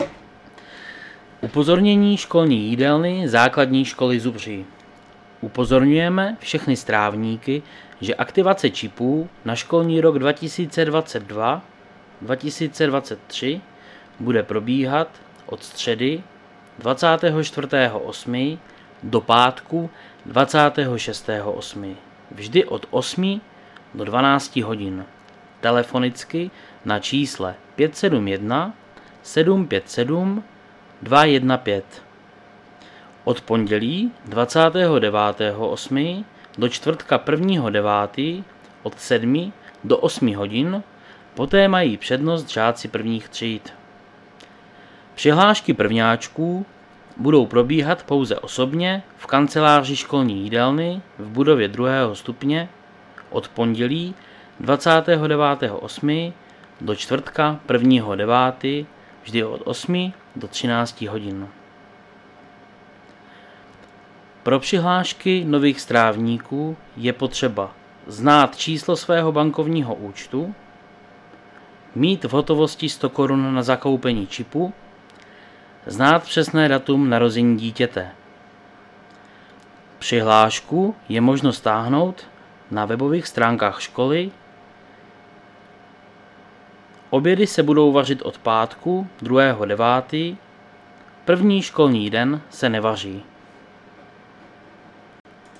Záznam hlášení místního rozhlasu 16.8.2022